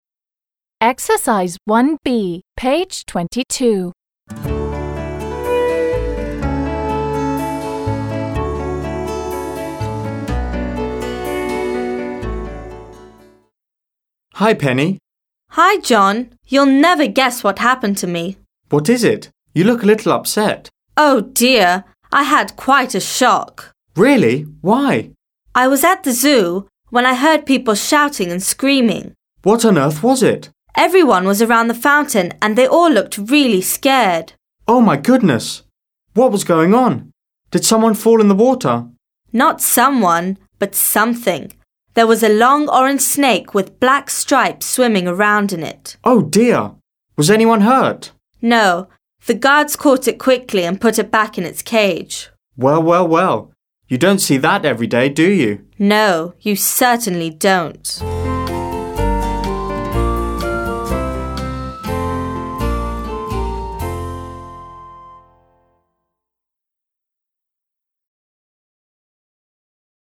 1. b) Предложения в упражнении 1а взяты из диалога между двумя друзьями.